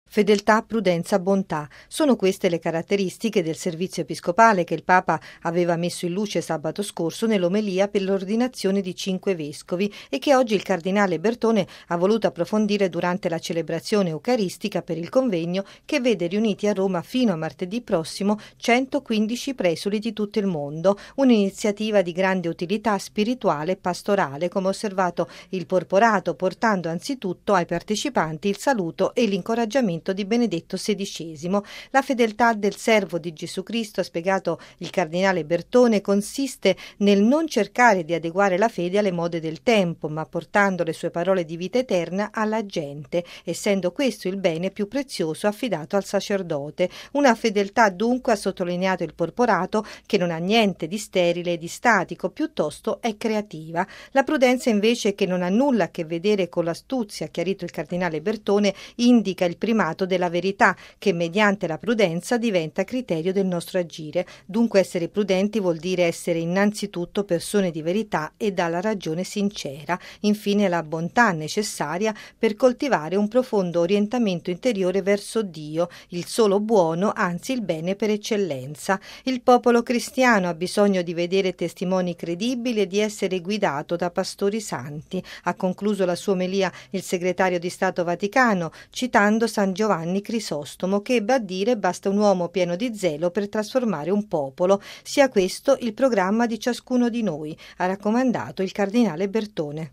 Il popolo cristiano ha bisogno di pastori santi: così, il cardinale Bertone nella Messa in San Pietro per i nuovi vescovi